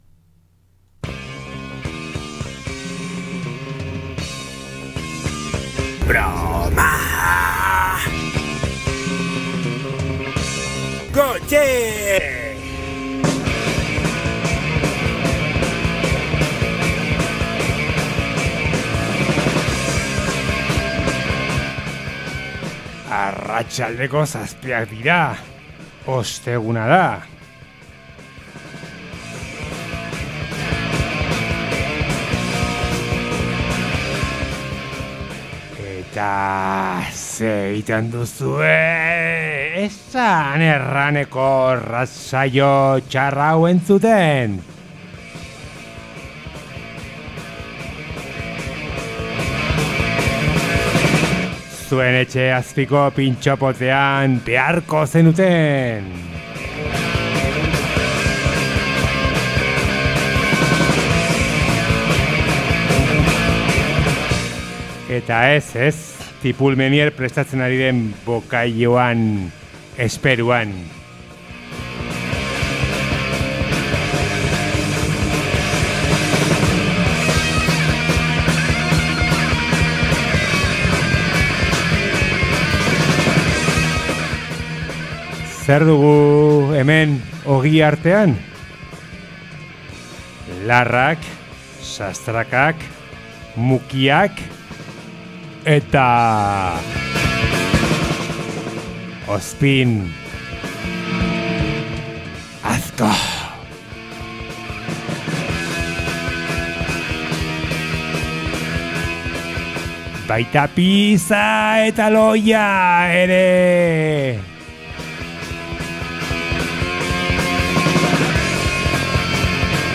2024ko azken Broma Gutxi irratsaioa izan dugu gaurkoa.
Eta, beti bezala, zuzenean jarri dizkigun biniloetako abestiez gain, hausnarketarako tartetxoa ere izan dugu ‘Motozerra ta bisturi’ atalean.